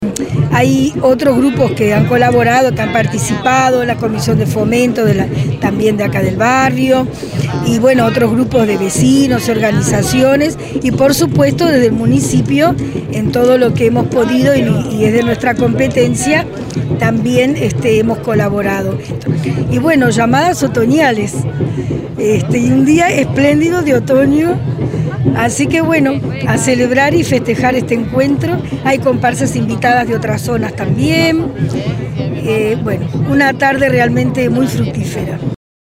sonia_misirian_alcaldesa_de_ciudad_de_la_costa.mp3